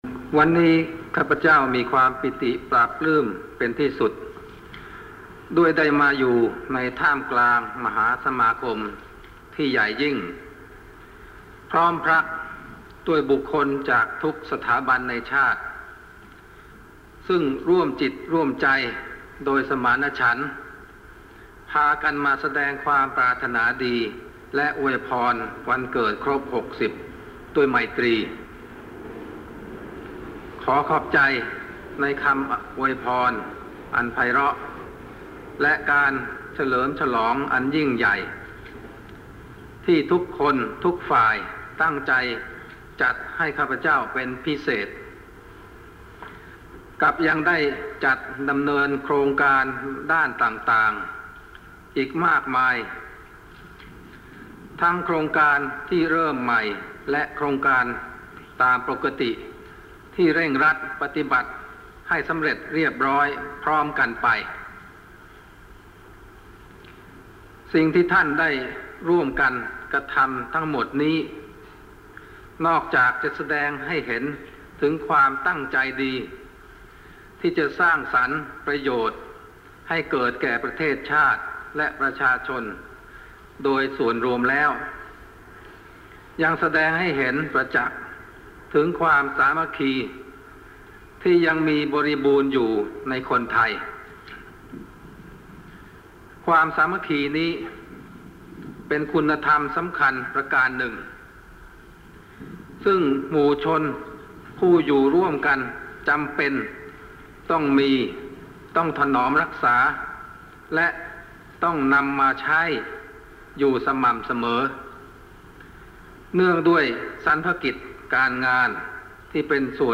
พระราชดำรัสพระบาทสมเด็จพระเจ้าอยู่หัว พระราชทานแก่คณะบุคคลซึ่งเข้าเฝ้าถวายพระพร 4 ธันวามคม 2530